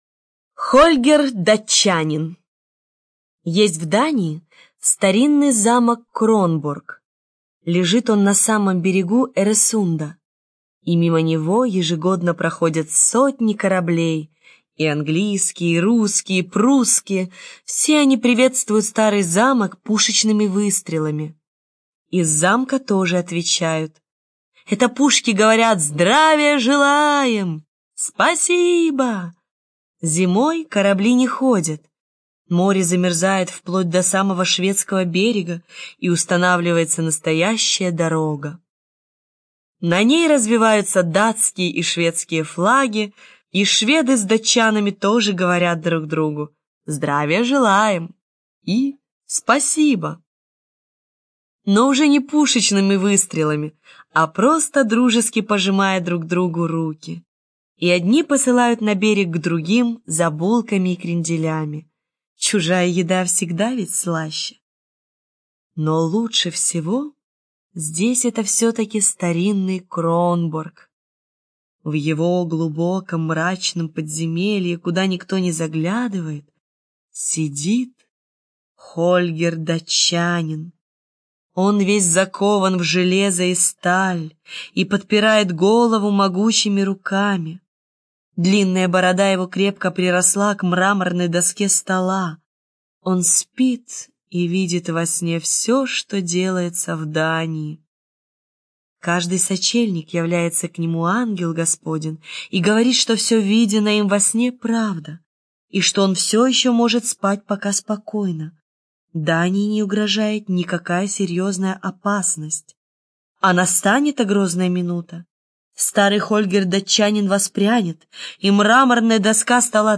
Хольгер Датчанин - аудиосказка Андерсена.